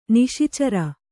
♪ niśi cara